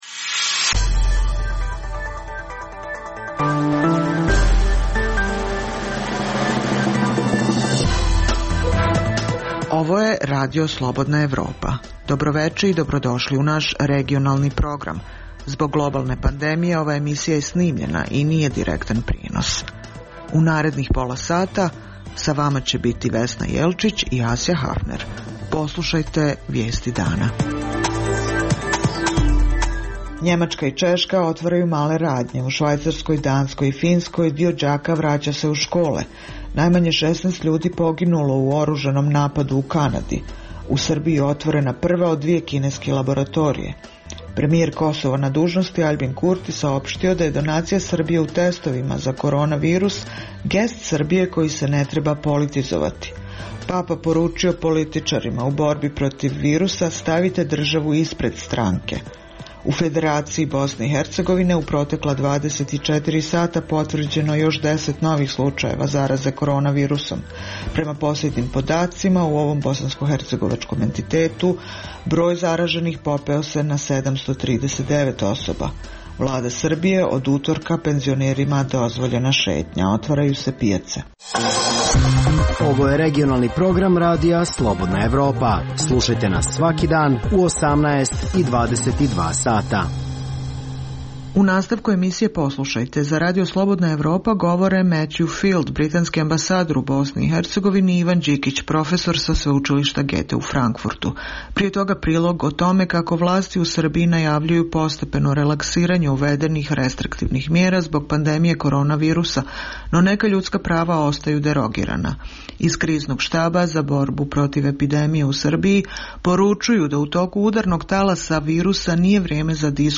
Zbog globalne pandemije, ova je emisija unapred snimljena i nije direktan prenos.